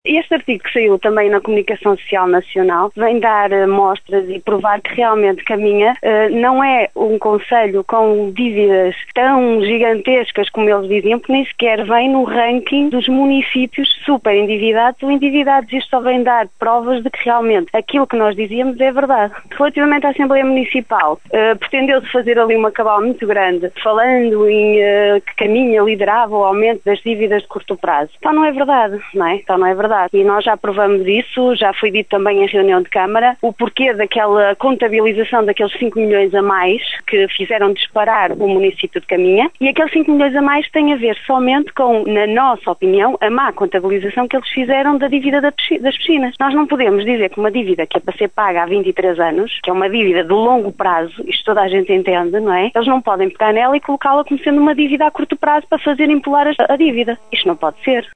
Em declarações à Rádio Caminha